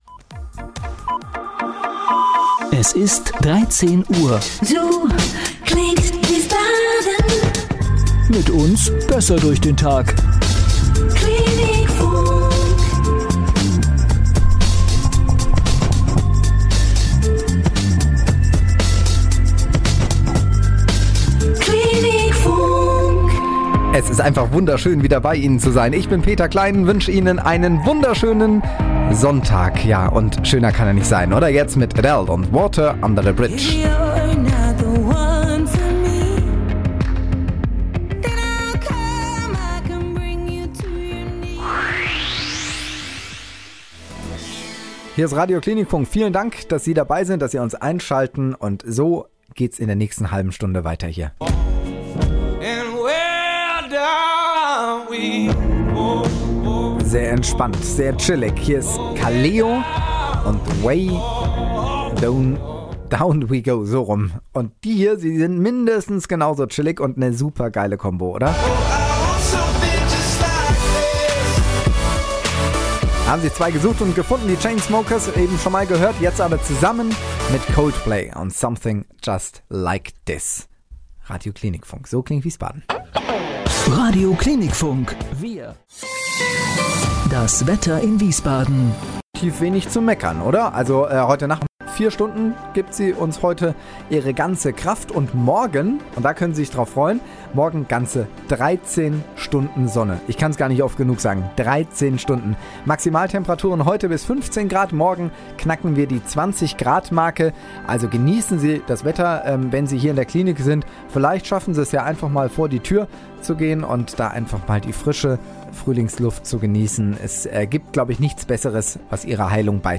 Moderation
AirCheck-long-2018.mp3